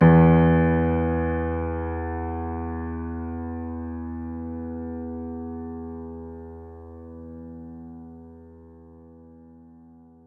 piano-sounds-dev
Vintage_Upright
e1.mp3